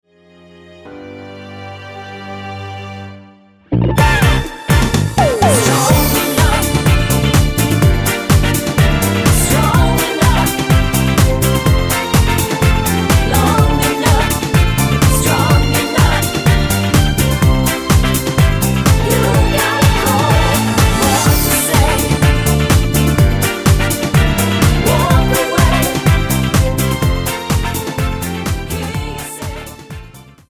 Tonart:Cm-Dm-Em mit Chor